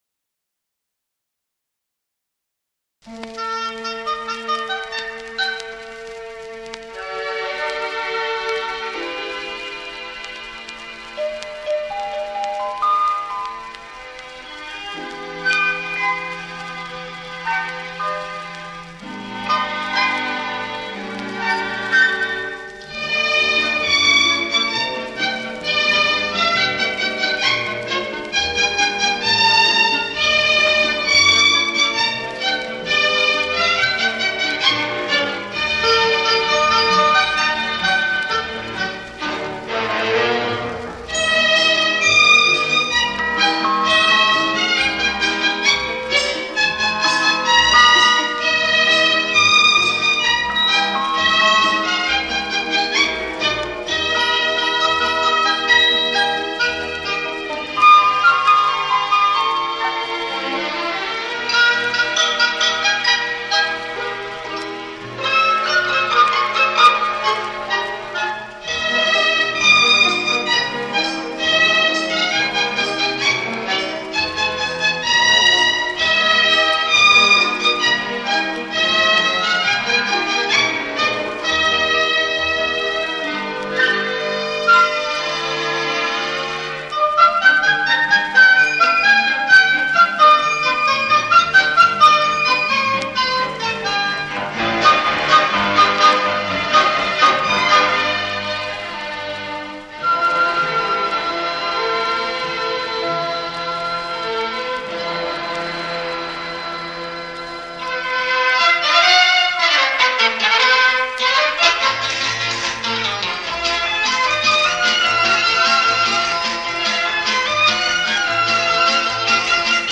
Music from the Soundtrack of